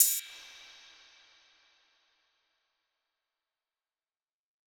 Crashes & Cymbals
Boomin - Cymbal 1.wav